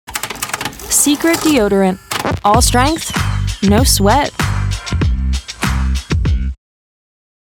hell, fein, zart, sehr variabel
Commercial (Werbung)